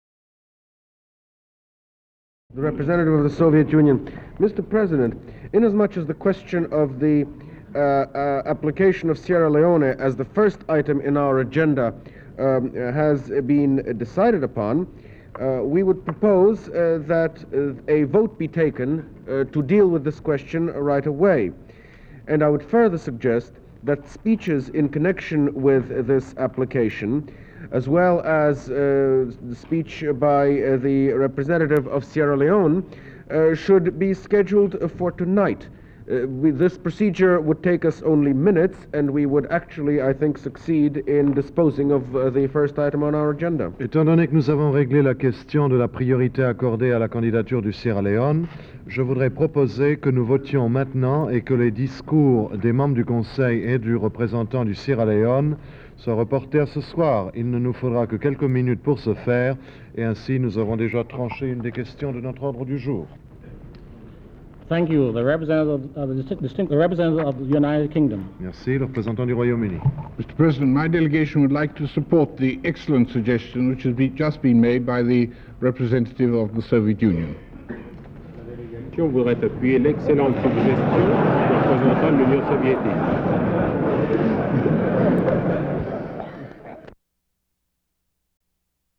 Unidentified U.K. delegate endorses a proposal from an unidentified USSR delegate